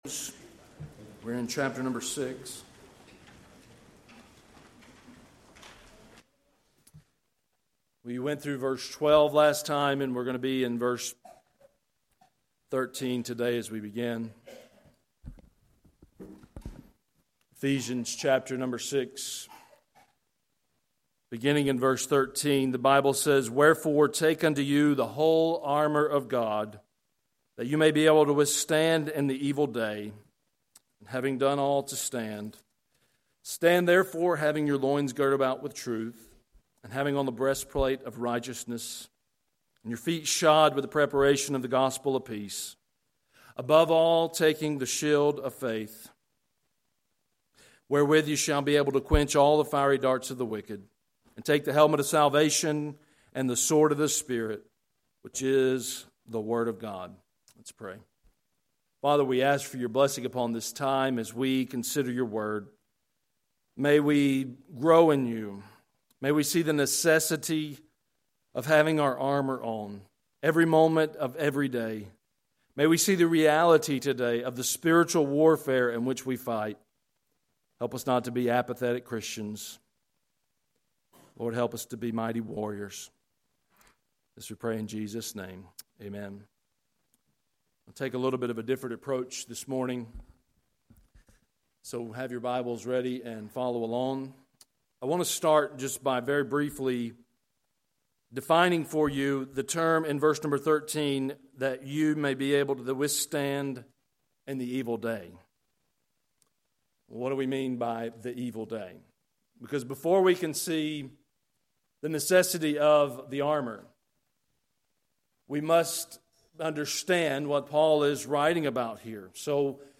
Sermons | Harpeth Baptist Church